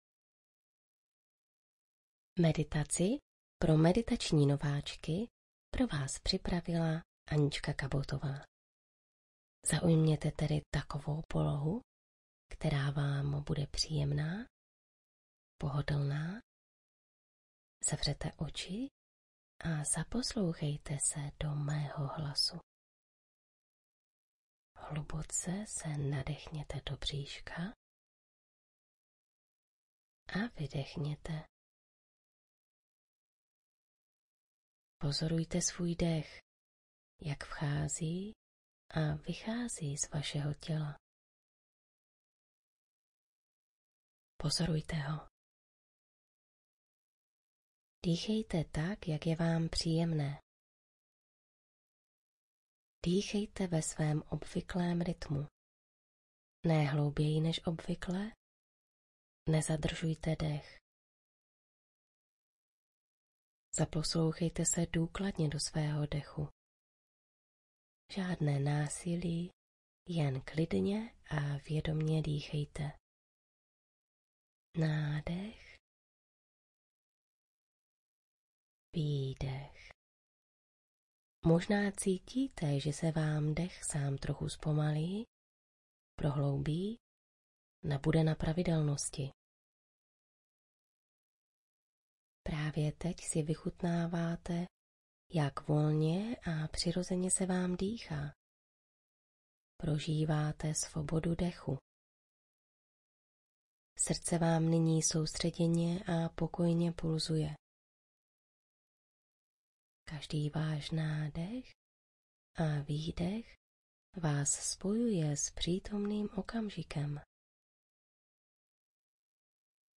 První nahrávka meditace je bez hudby.